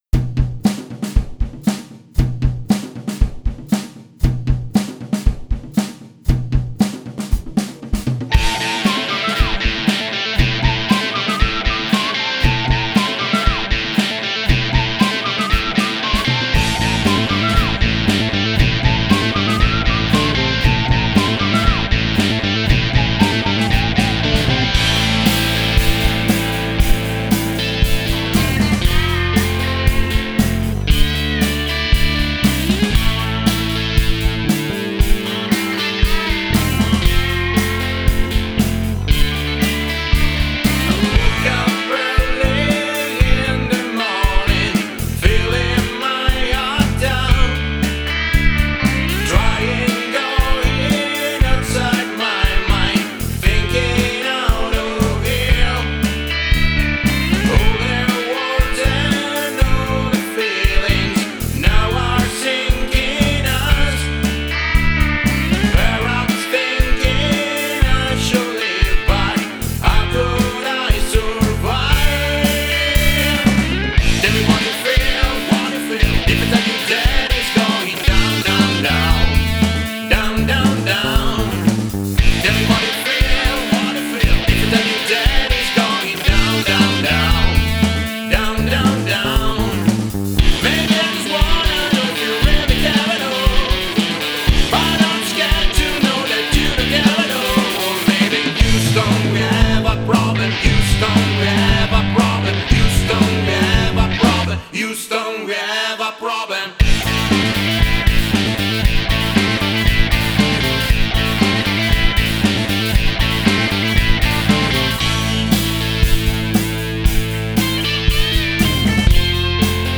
batteria
tastiere
basso
due chitarre